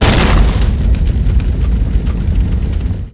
1 channel
HitFire.wav